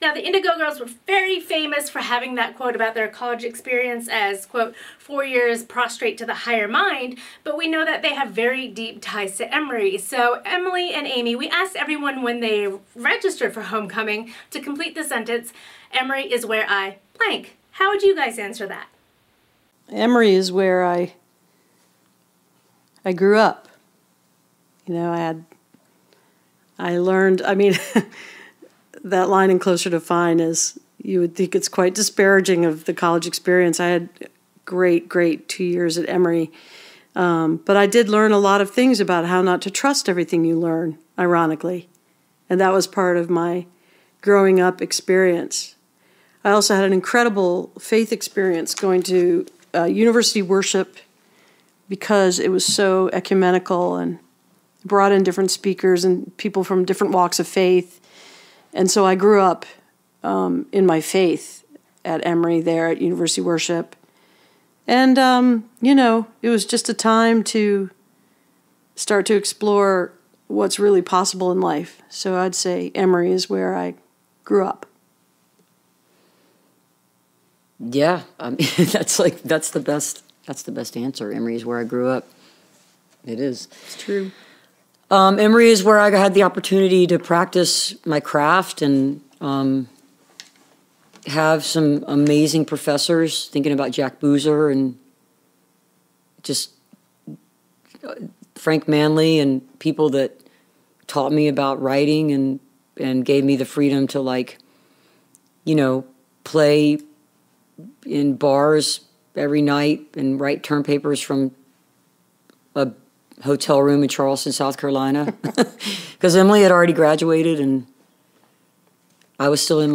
(captured from the youtube livestream)
08. interview (indigo girls) (3:17)